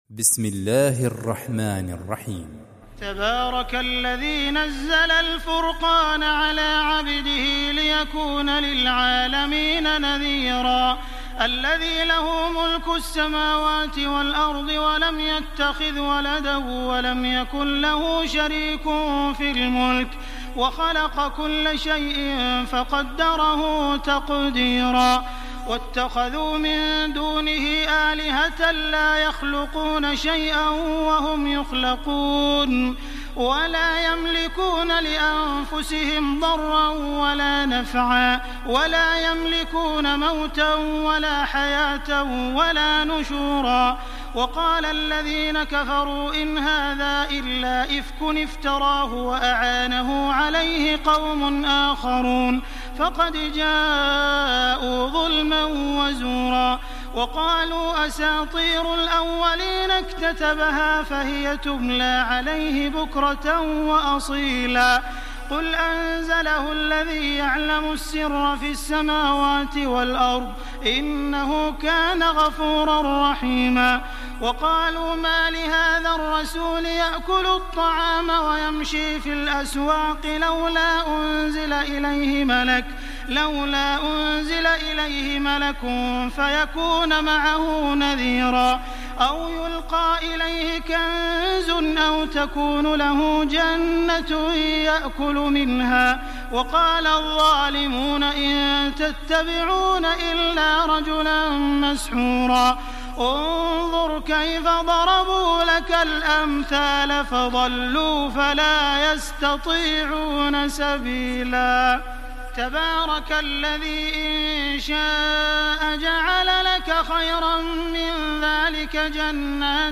استمع الى باقي السور للقارئ عبد الرحمن السديس